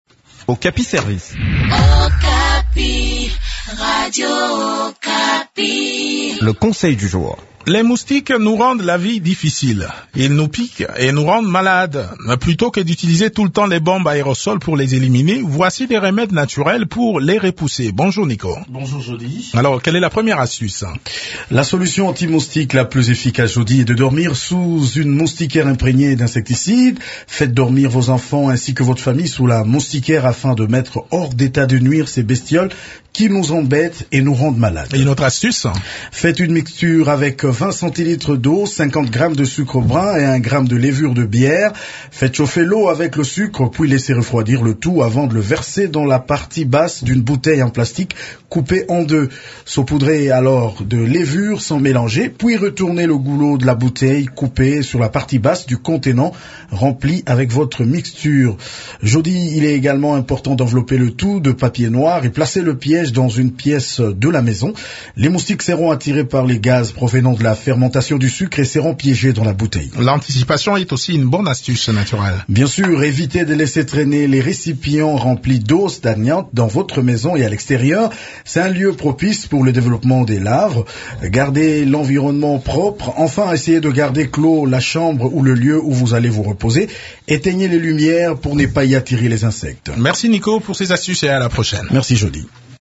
Plutôt  que d’utiliser tout le temps  les bombes aérosols pour les éliminer, voici des remèdes naturels pour les repousser. Découvrez-les dans cette chronique